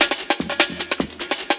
61 LOOP05.wav